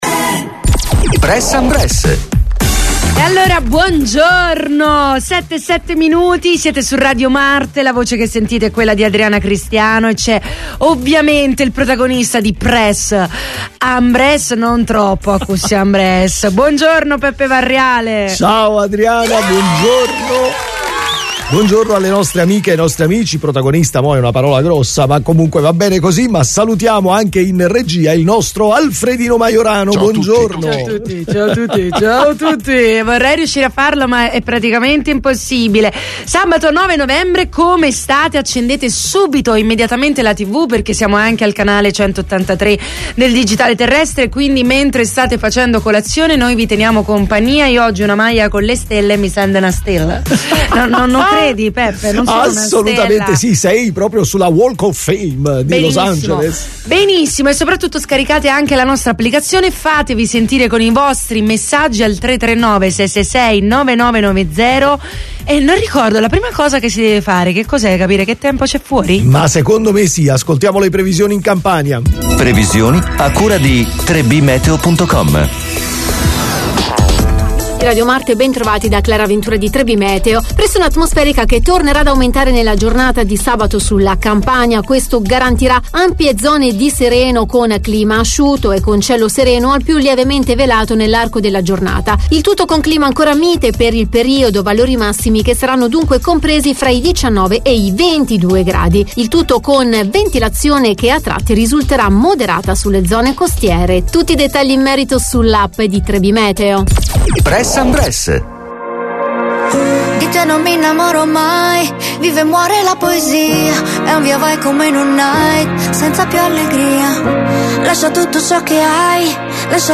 COLLEGAMENTI IN DIRETTA CON I CANALI TV ALL NEWS, OSPITI AL TELEFONO DAL MONDO DELLO SPETTACOLO, DELLA MUSICA, DELLA CULTURA, DELL’ARTE, DELL’INFORMAZIONE, DELLA MEDICINA, DELLO SPORT E DEL FITNESS.